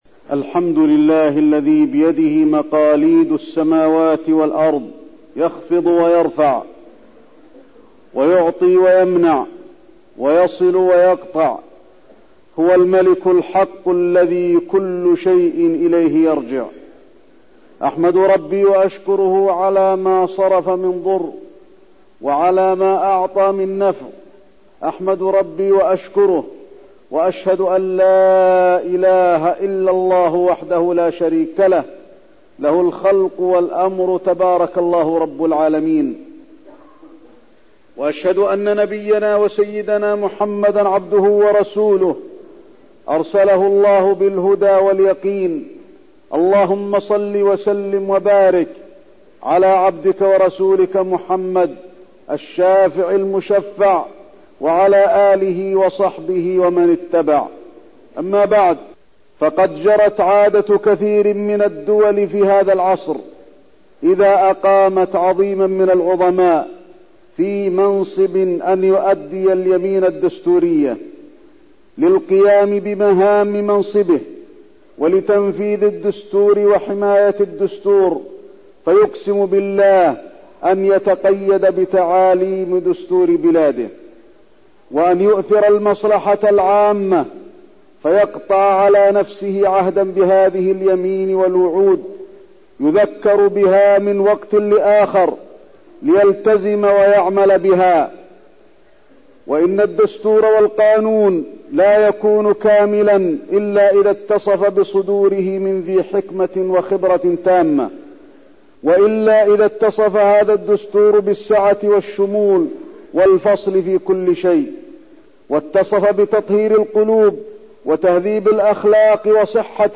تاريخ النشر ٢٩ رجب ١٤١٣ هـ المكان: المسجد النبوي الشيخ: فضيلة الشيخ د. علي بن عبدالرحمن الحذيفي فضيلة الشيخ د. علي بن عبدالرحمن الحذيفي الالتزام بالشريعة والوفاء بعهد الله